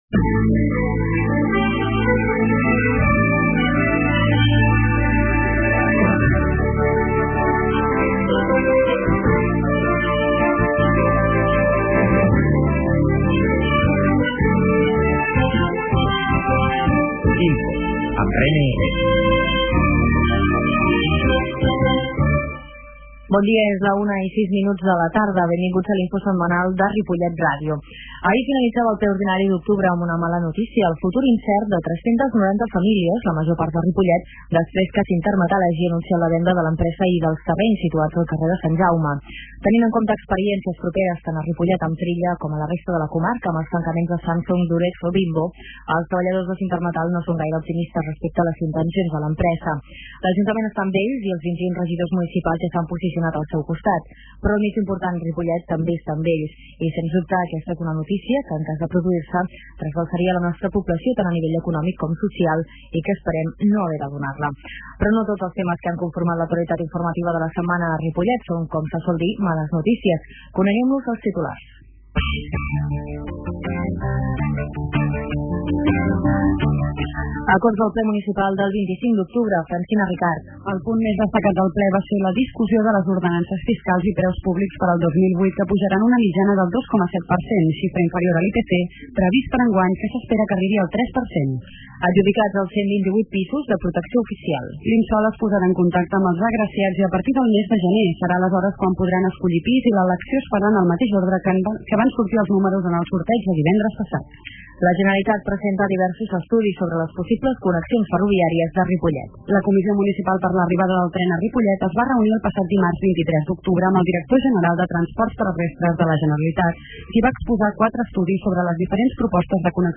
Comunicació INFO de la setmana: 26 d'octubre -Comunicació- 25/10/2007 Escolteu en directe per la r�dio o la xarxa el resum de not�cies de Ripollet R�dio, que s'emet els divendres a les 13 hores.